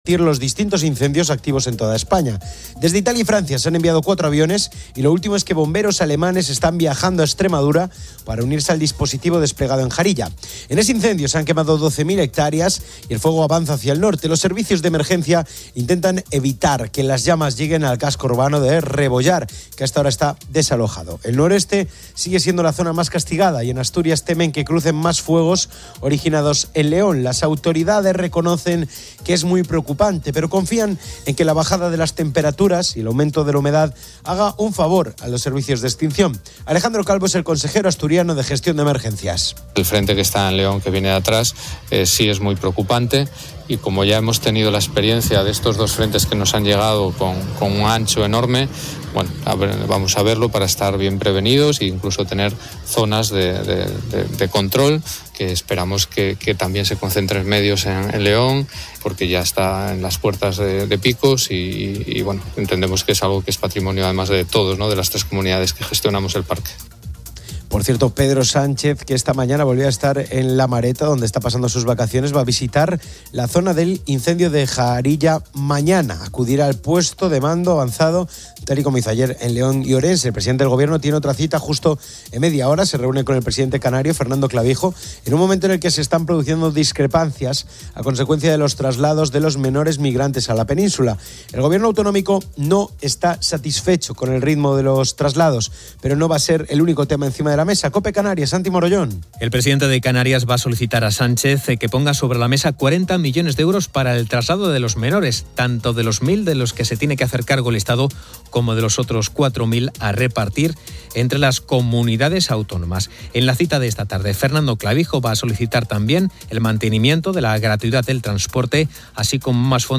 El audio proporciona un resumen de varias noticias de actualidad en España. Se informa sobre los numerosos incendios forestales activos en todo el país, con especial atención a Extremadura (incendio de Jarilla), Galicia (Ourense) y Castilla y León (Zamora y León).